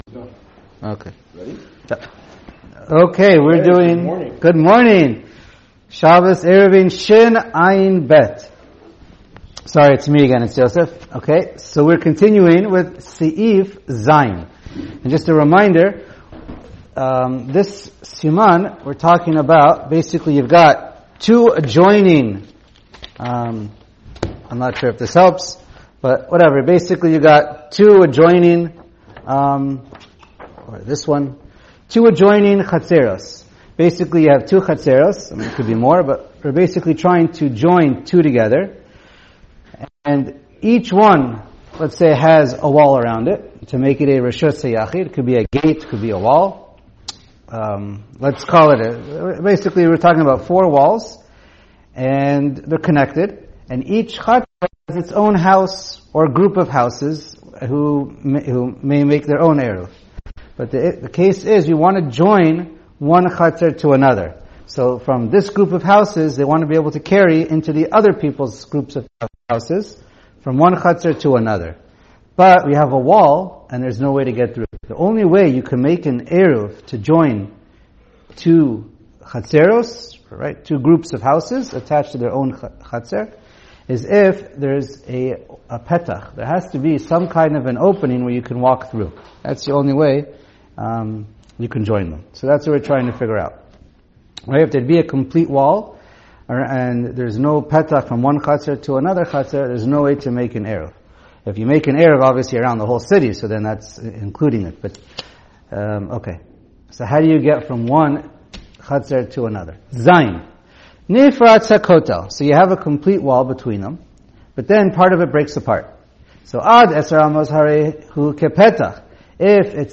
Shiur provided courtesy of Shulchan Aruch Yomi